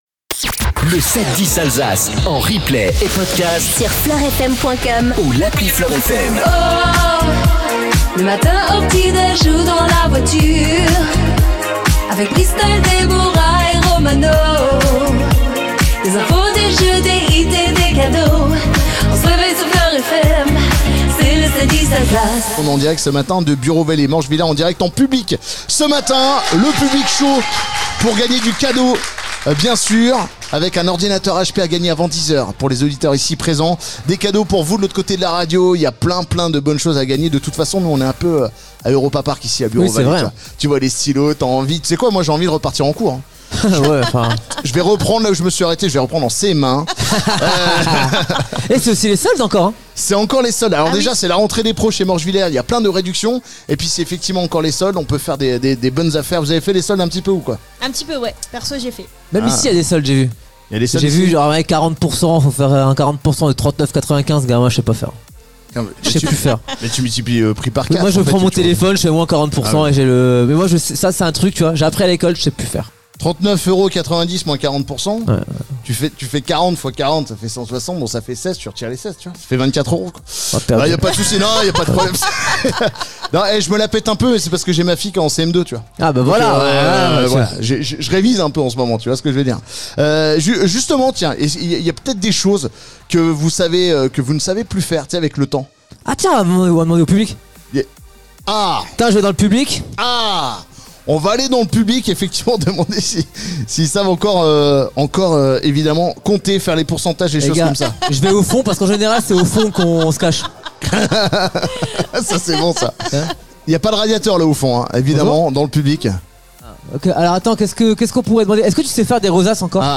M2 COLOR PODCAST 710 ALSACE RADIO BUREAU VALLEE FLOR FM Vendredi 24 janvier 0:00 41 min 17 sec 24 janvier 2025 - 41 min 17 sec LE 7-10 DU 24 JANVIER Retrouvez les meilleurs moments du 7-10 Alsace avec M2 Color , votre façadier dans le Haut-Rhin, en direct de Bureau Vallée .